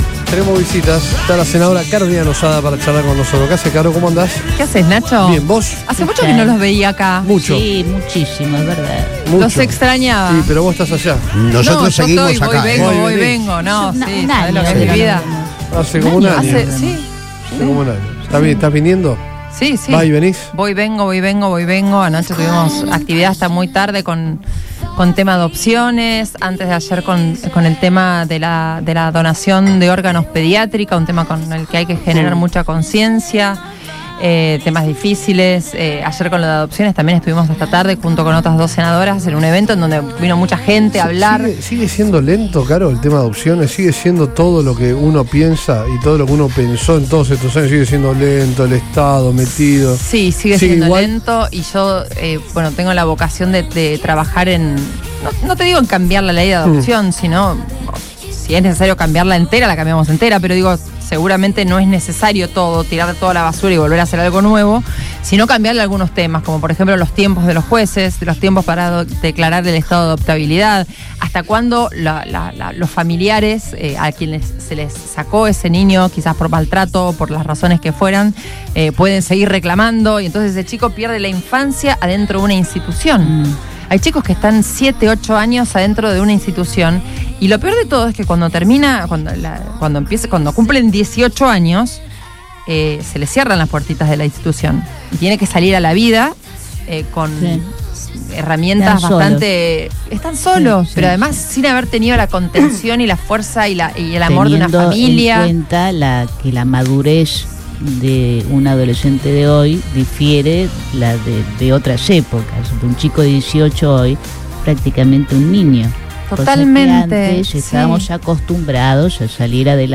La senadora nacional Carolina Losada visitó los estudios de Radio Boing y dialogó con el equipo de Todo Pasa turno tarde sobre la agenda legislativa que viene llevando adelante en el Congreso Nacional.